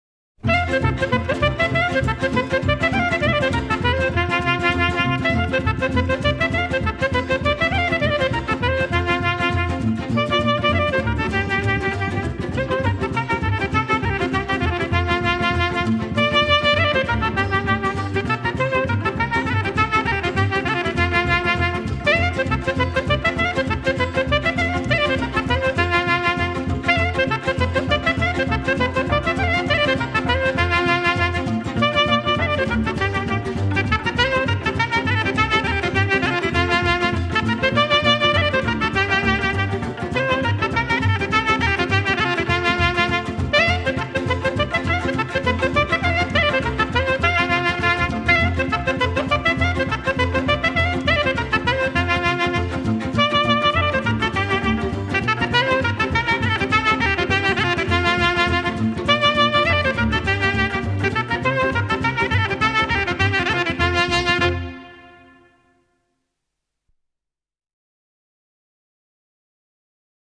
cu acompaniamentul orchestrei